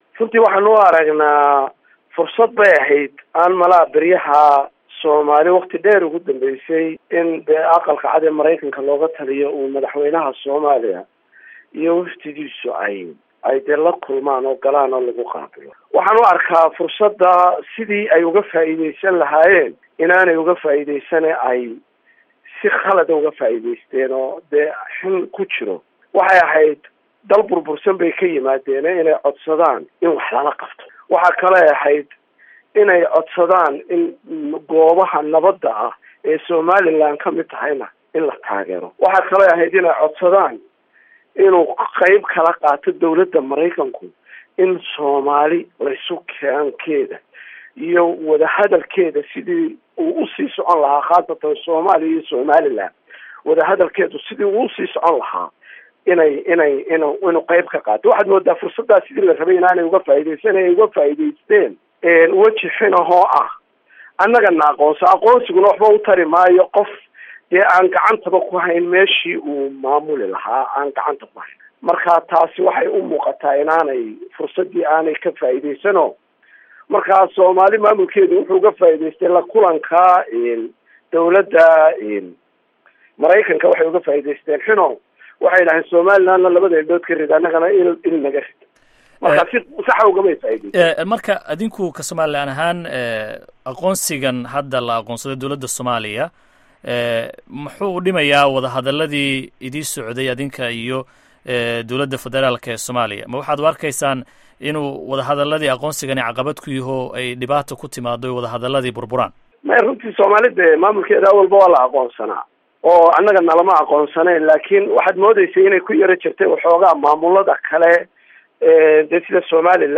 Wareysiga Wasiirka Arrimaha Gudaha ee Soomaaliland